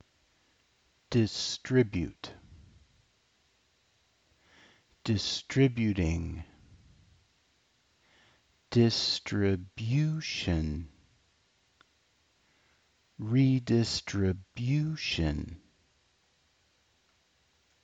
• disTRIbute
• disTRIbuting
• distriBUtion
• redistriBUtion